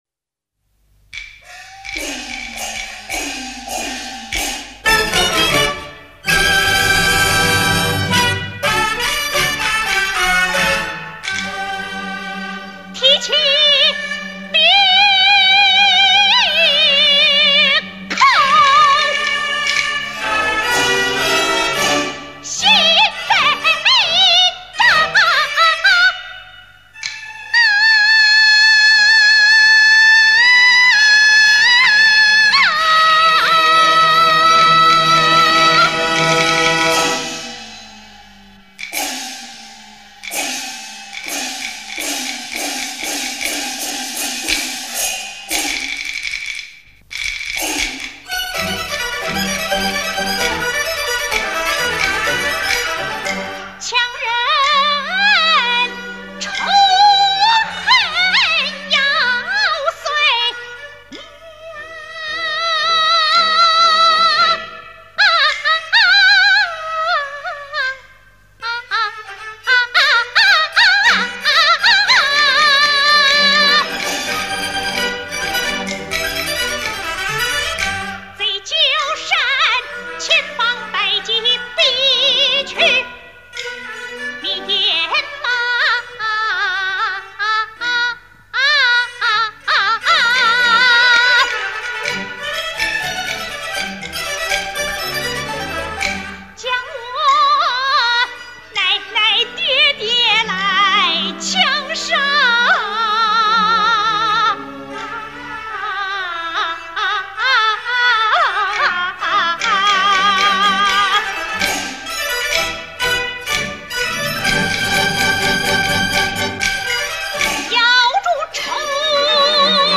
1971年錄製
珍貴的歷史錄音